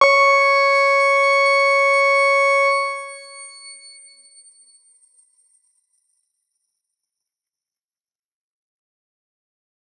X_Grain-C#5-mf.wav